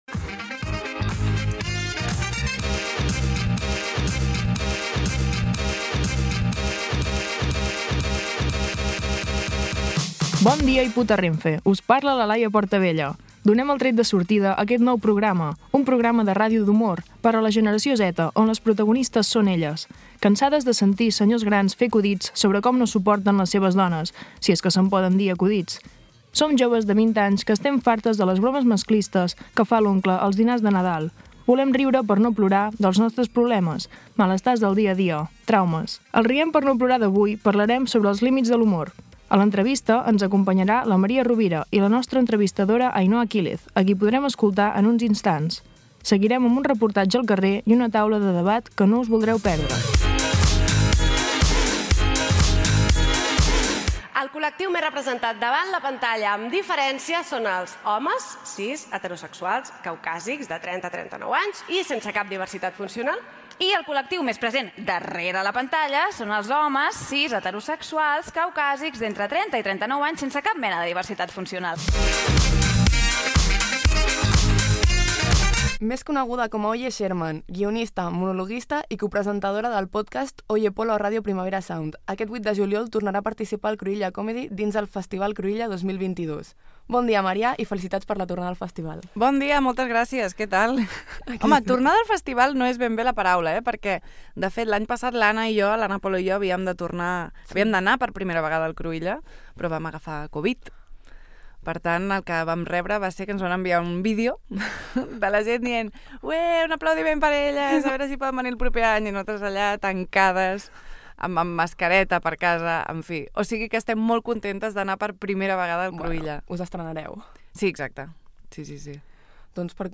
Podcast d'humor amb la vocació de donar veu a altres formes de fer riure. Amb entrevista